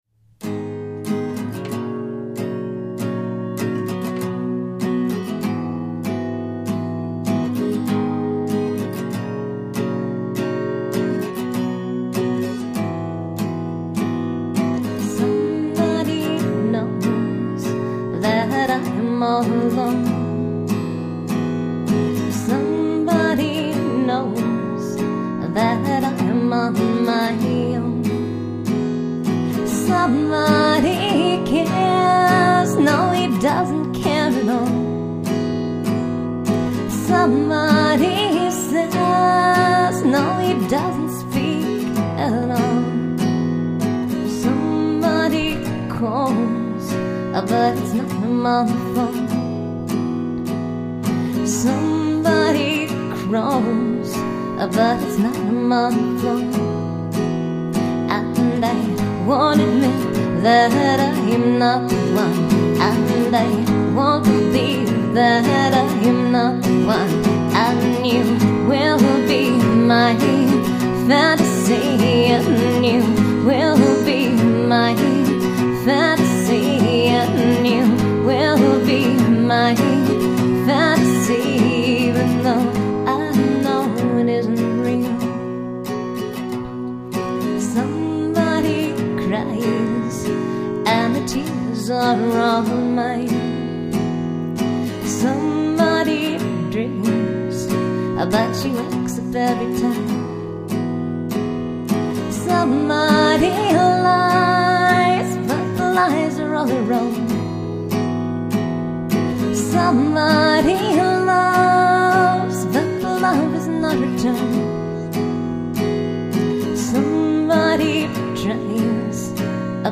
64kbps mono MP3
guitar and vocals